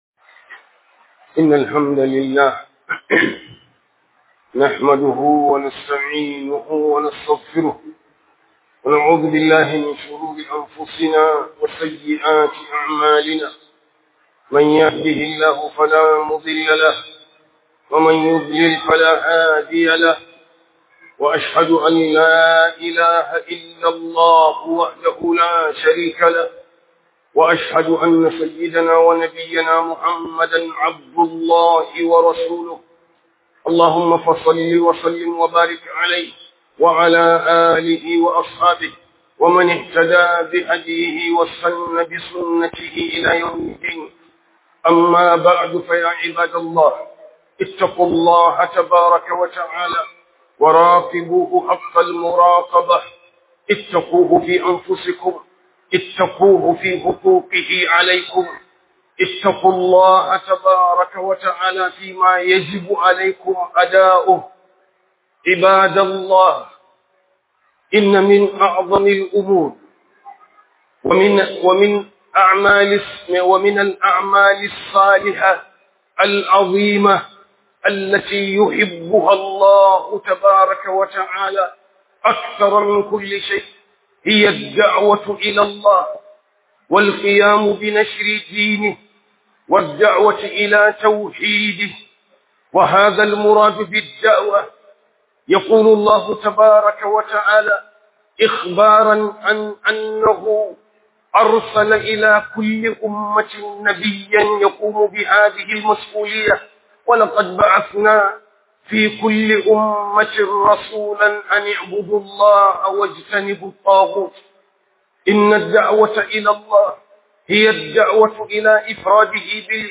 Falalar Da'awa Zuwa ga Allah - Huduba